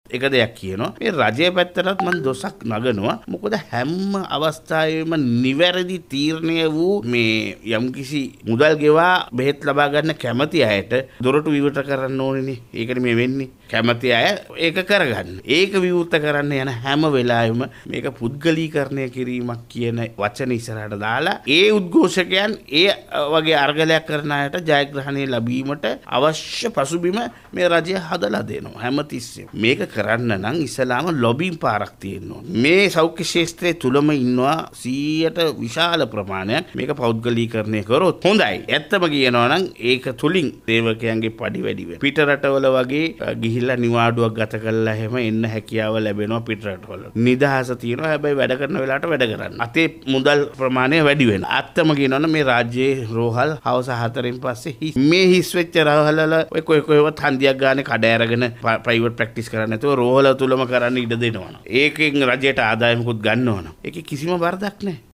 ඔහු මෙම දහස් දැක්වීම සිදු කළේ කොළොඹ පැවති මාධ්‍ය හමුවකට එක්වෙමින් .